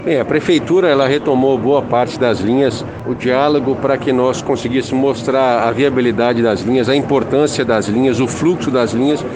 A declaração foi em resposta ao questionamento do Diário do Transporte que esteve presente ao evento.